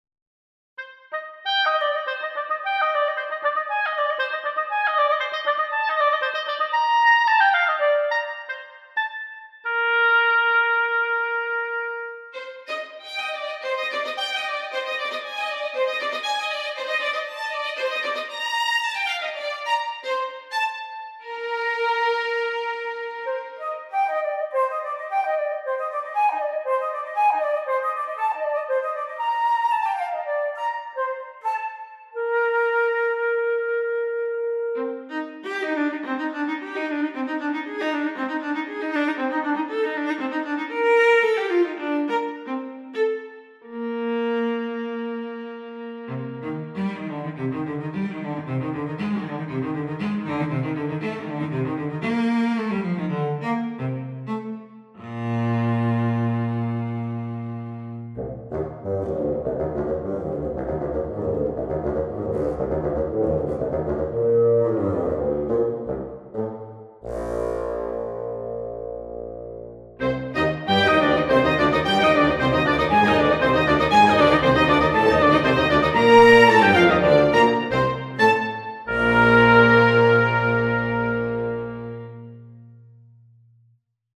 Demo Melody
Listen to the melody with the Oboe
I changed the instrument after the Oboe...